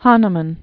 (hänə-mən, -män), (Christian Friedrich) Samuel 1755-1843.